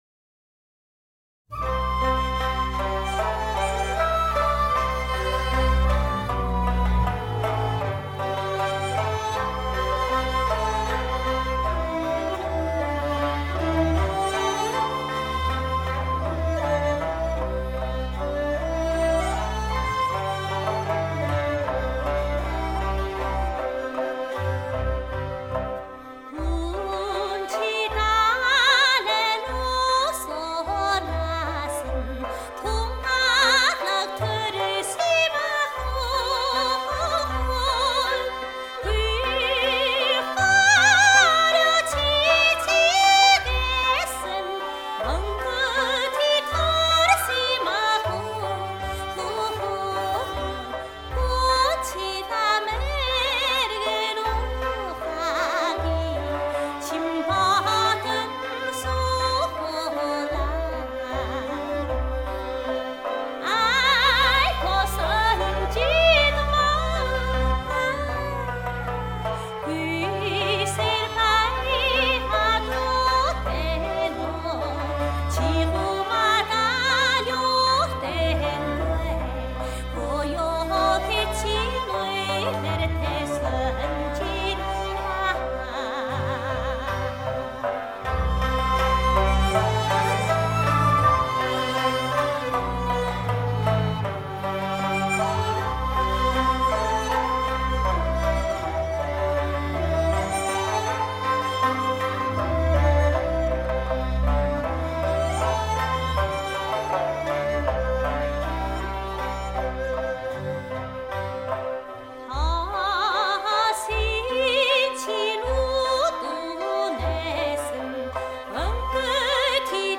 3D录制